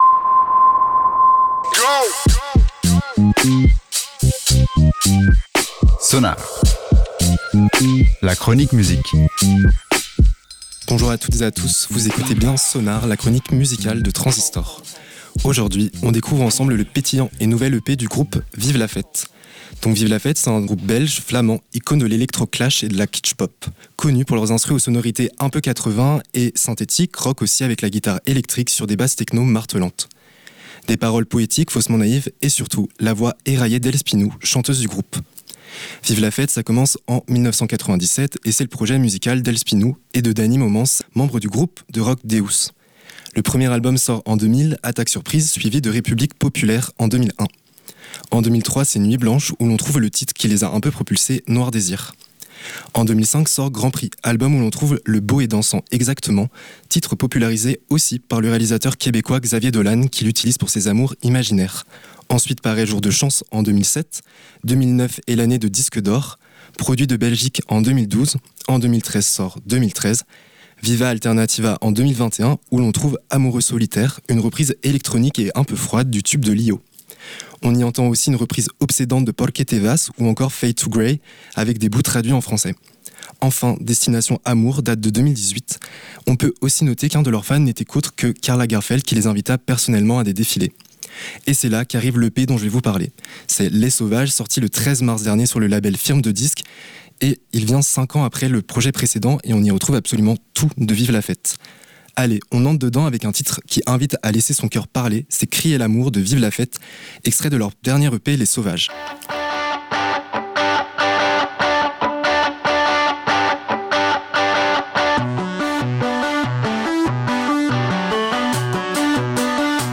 L'EP est évolutif, il infléchit vers le joyeux au fil des sons et des thèmes.
"Emotions" est très synthpop et rappelle du Eurythmics.